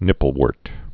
(nĭpəl-wûrt, -wôrt)